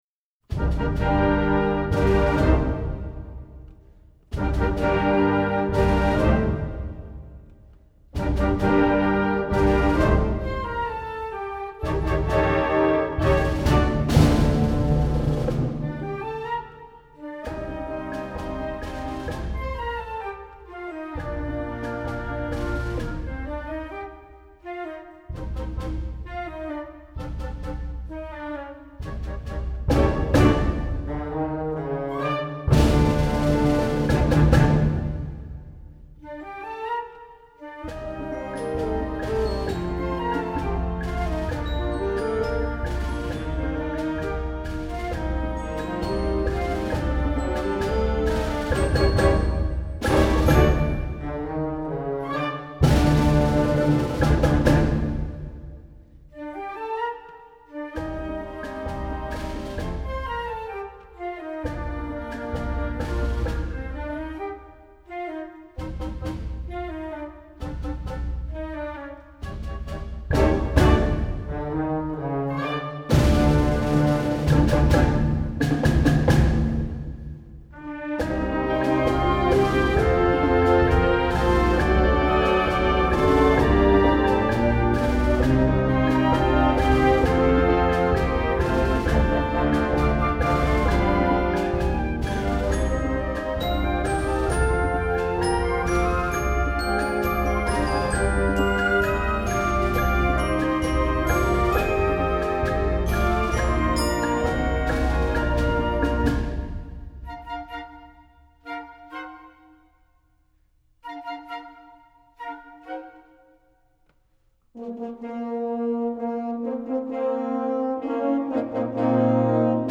Genre: Band
Flute
Bb Clarinet 1/2
Alto Saxophone
Bb Trumpet 1/2
Trombone
Tuba
Percussion 2 (snare drum, bass drum)